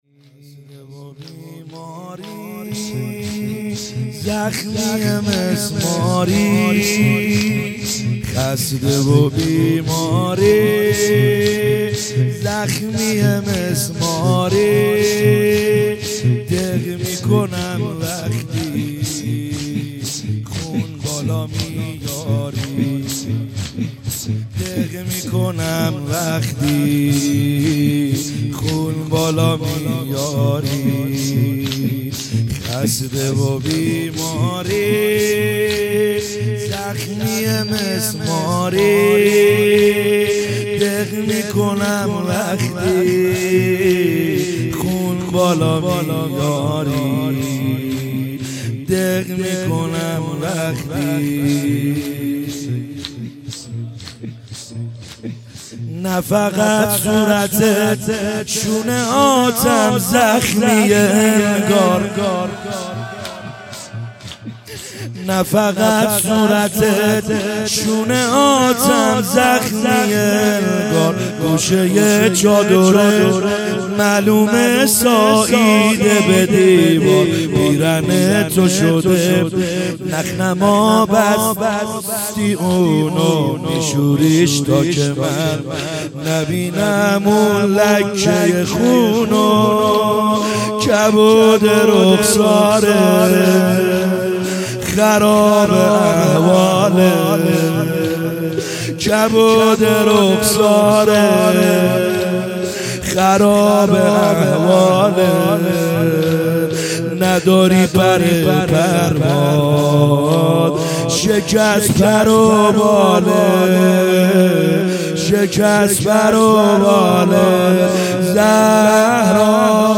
خیمه گاه - بیرق معظم محبین حضرت صاحب الزمان(عج) - زمینه | خسته و بیماری زخمیه مسماری